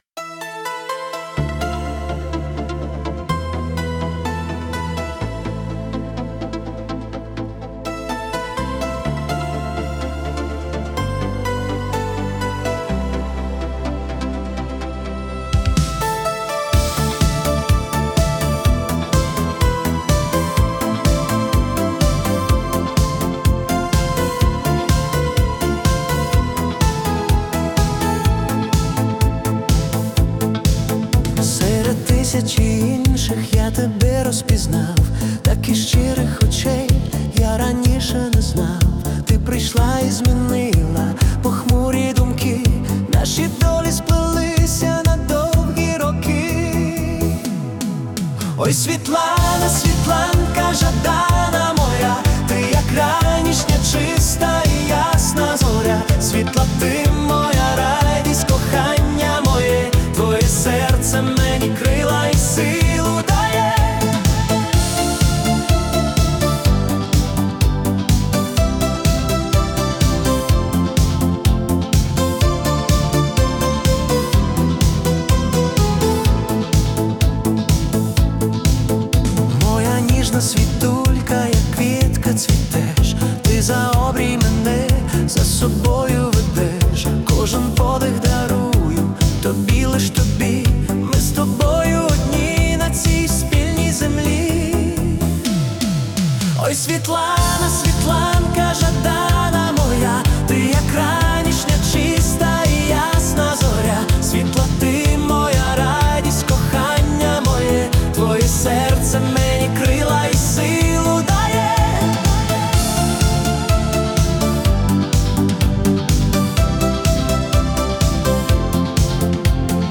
Melodic Pop / Romantic